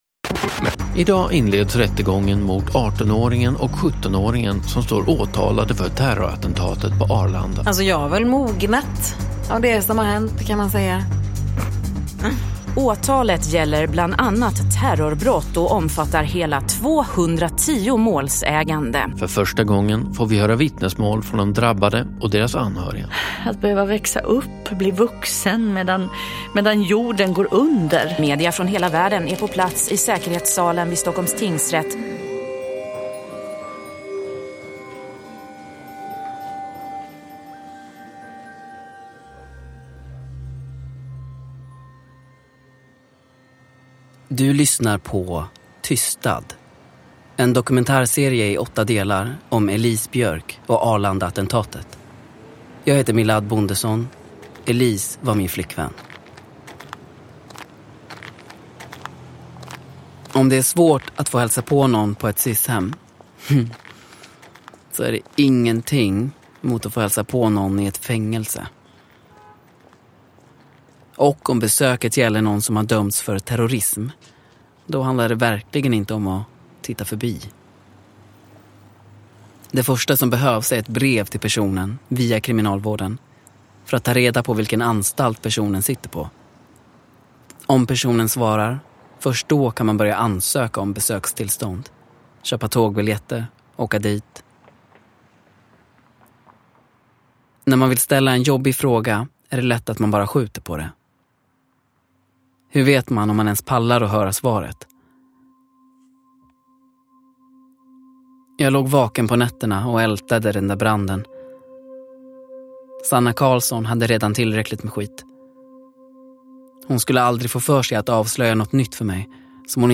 Tystad - 8 – Ljudbok – Laddas ner
TYSTAD är en fiktiv dokumentärserie av Åsa Anderberg Strollo och Lisa Bjärbo.